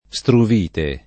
[ S truv & te ]